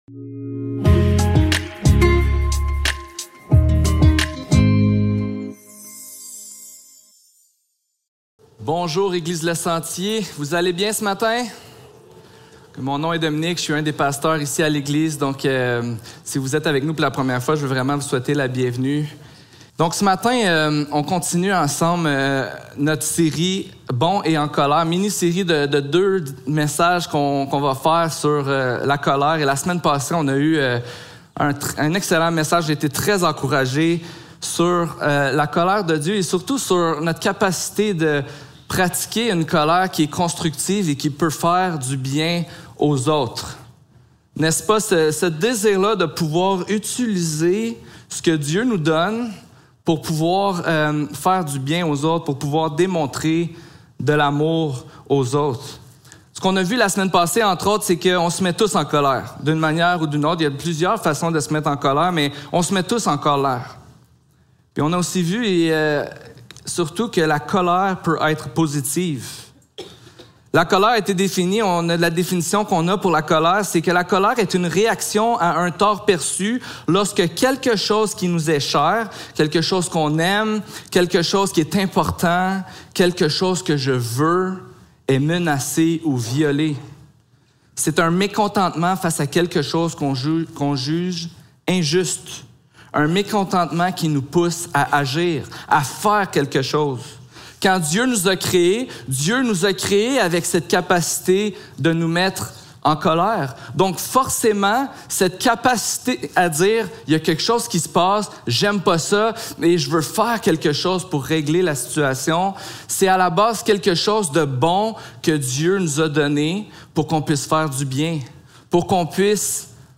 Jacques 4.1-10 Service Type: Célébration dimanche matin Description